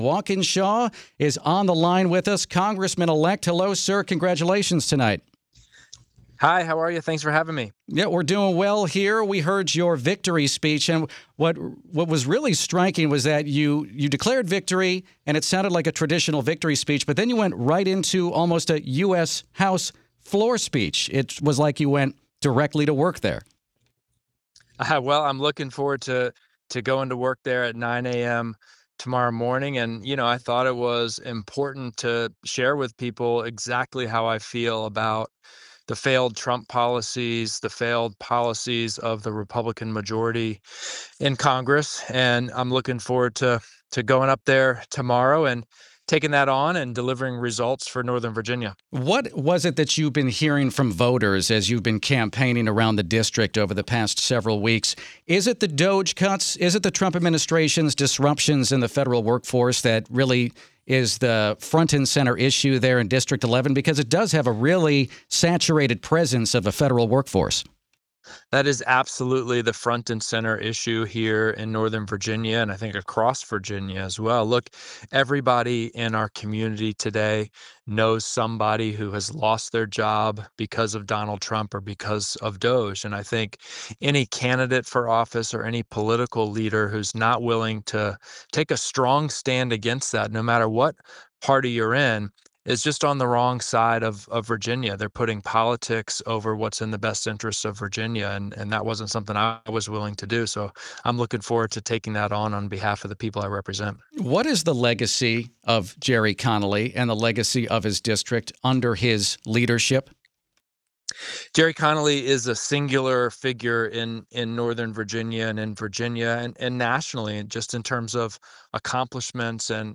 walkinshaw-on-wtop.mp3